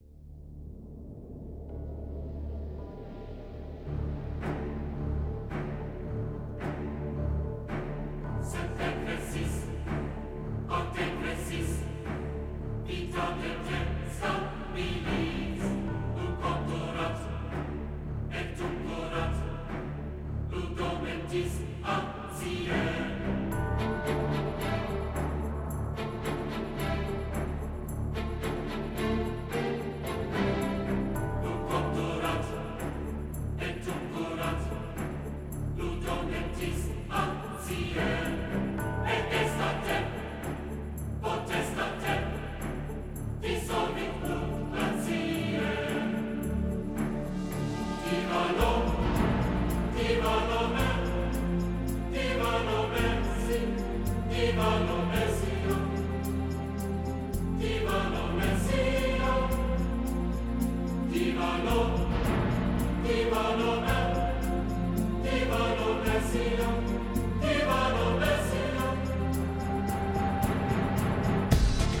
浩瀚、庄严、古典而又现代的音律所震撼，
但细听时又能感受到歌曲释放出的一些摇滚和流行的元素，
这首曲子气势磅礴，威风凛凛，有很典型的欧洲教堂风格，
因此多了几分神圣感，
而电子乐的编曲形式没有影响到这首曲子所营造的庄严肃穆的气氛。
此曲低音凶猛，声场开阔，旋律很吸引人，音乐性和音响性都十分出色。